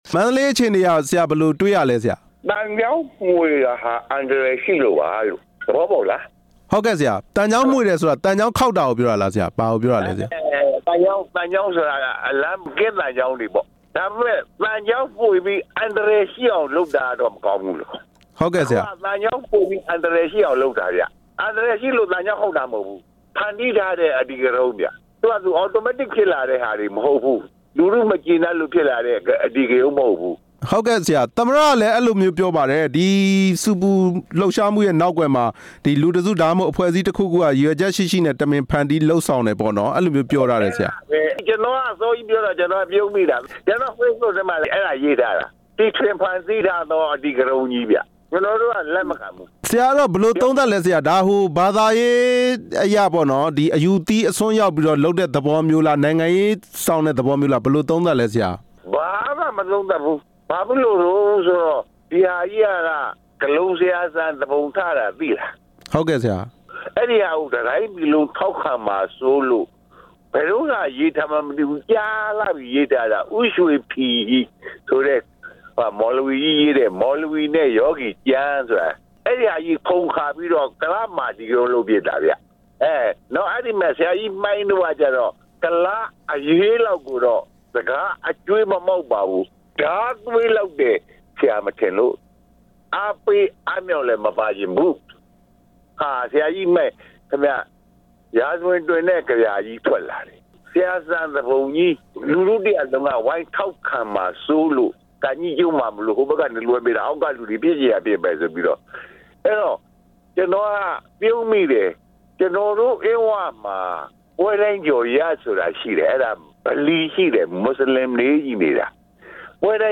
နဲ့ မေးမြန်းချက်